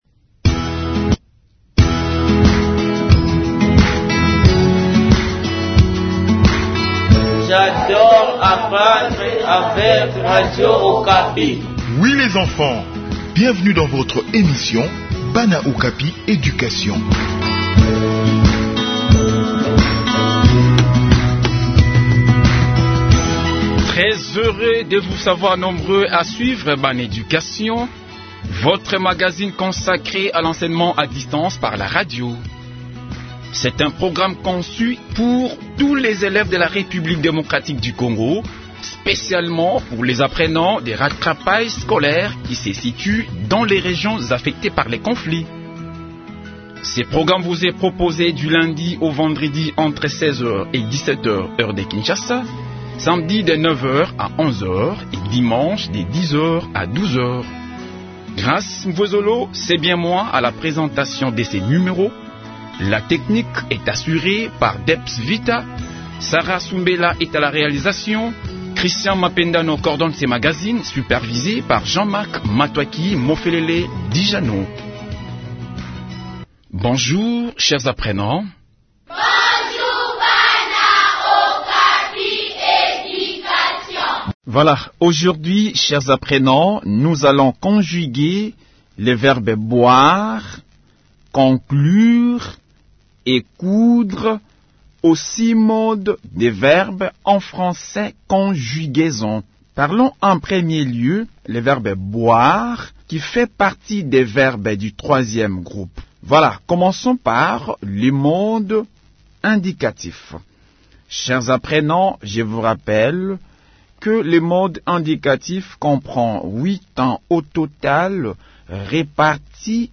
Réécoutez l'intégralité de cette leçon pour en savoir plus !